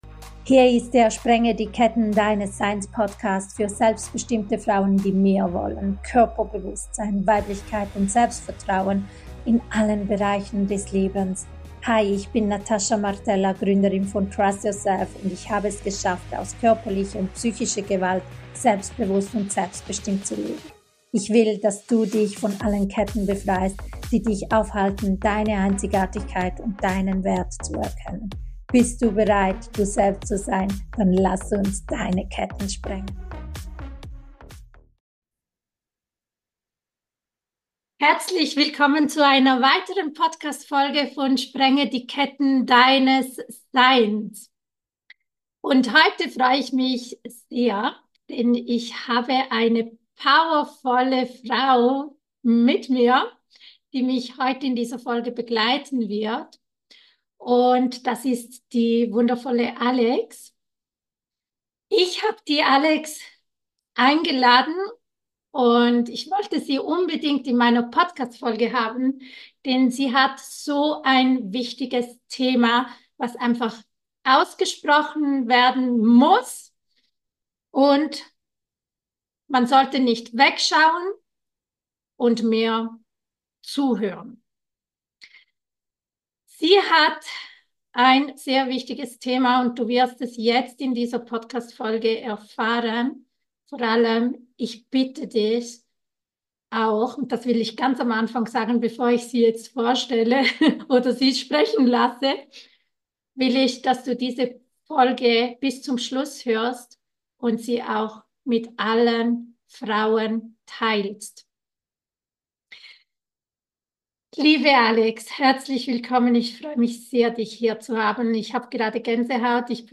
#13 Interview